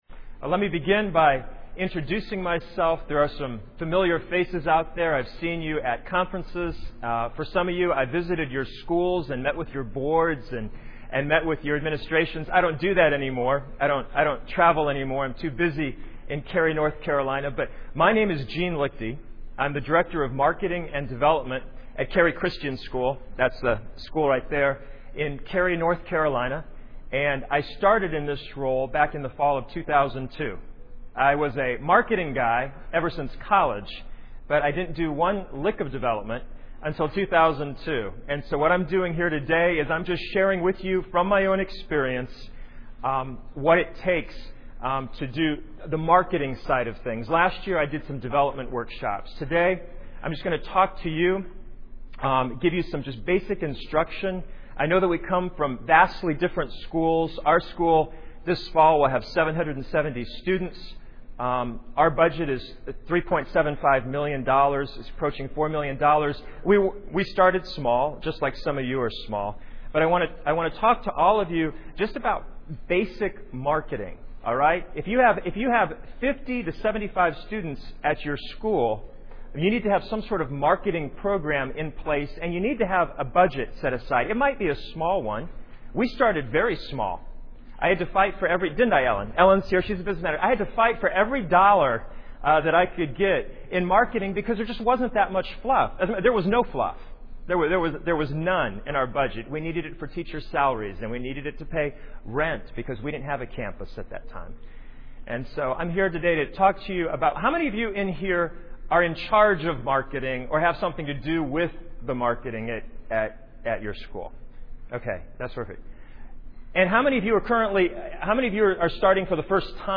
2007 Workshop Talk | 0:54:39 | Fundraising & Development
Additional Materials The Association of Classical & Christian Schools presents Repairing the Ruins, the ACCS annual conference, copyright ACCS.